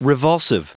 Prononciation du mot : revulsive